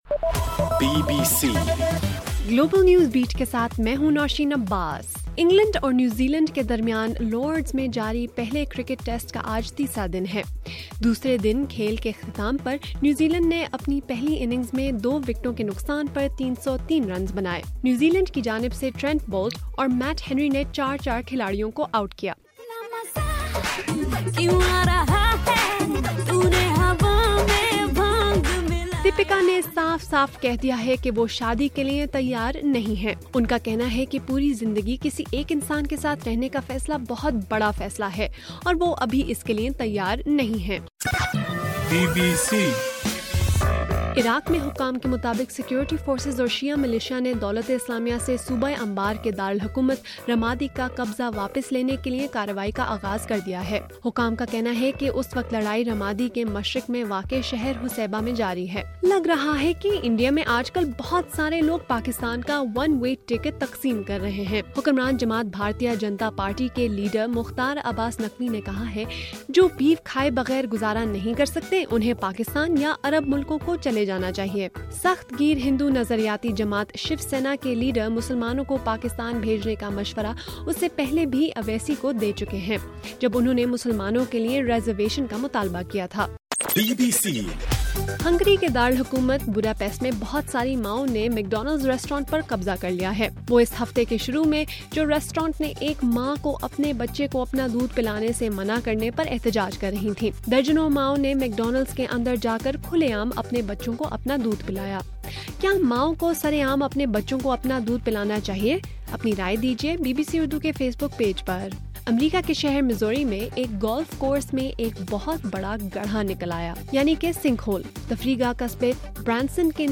مئی 23: رات 9 بجے کا گلوبل نیوز بیٹ بُلیٹن